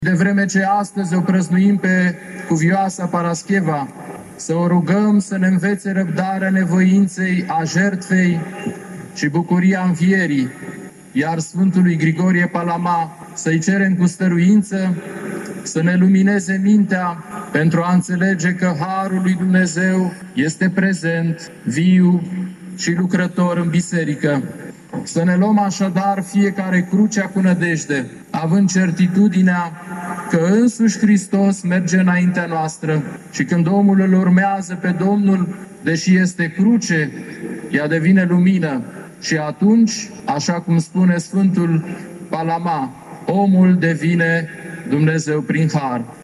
Ceremonialul s-a desfășurat, ca în fiecare an, pe un podium special amenajat pe pietonalul Ștefan cel Mare și Sfânt, în dreptul Catedralei Mitropolitane.
14-oct-rdj-12-IPS-Filotheos-traducere.mp3